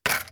hook.wav